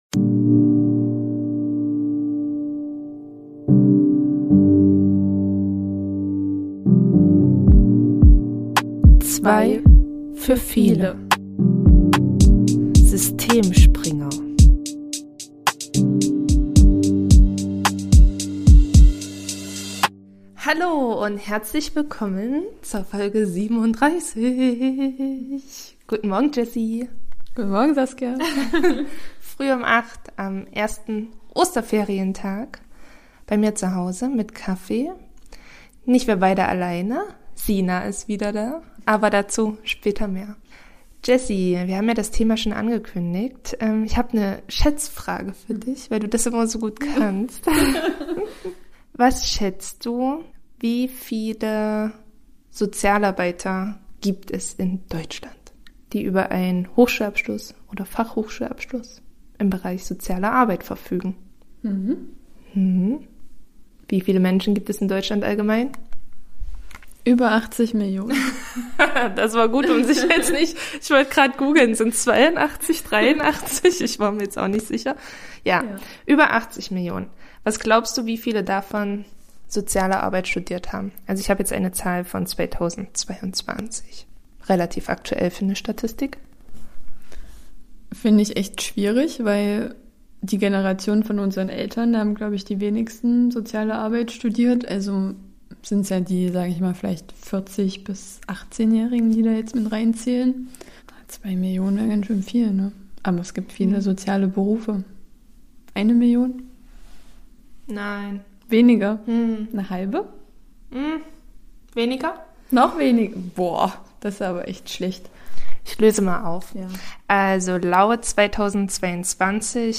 Wir wollen euch in dieser Folge einen kleinen Einblick in unsere Studienzeit zeigen und haben zudem eine Studentin zu Gast, die gerade im 4. Semester Soziale Arbeit studiert. Welche Vor- und Nachteile ein Vollzeitstudium aufzeigt, oder ob vielleicht das Duale Studium die bessere Wahl ist, werden wir in dieser Folge beleuchten.